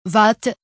vat